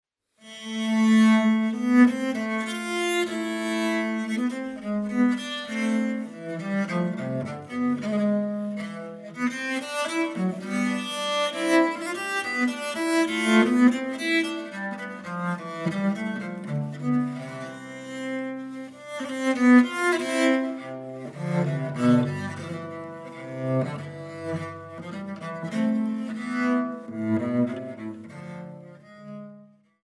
Laute
Viola da Gamba
Flöte/Dulzian
Violine/Viola